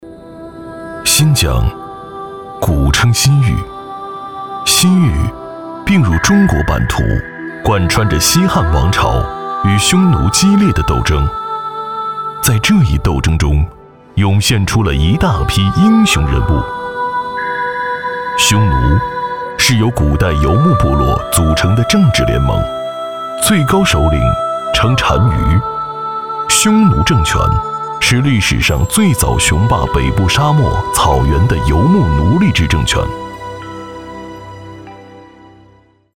纪录片男175号（丝路新疆
娓娓道来 文化历史
大气洪亮，年轻时尚男音，擅长旁白、历史文化记录片讲述、科技宣传片。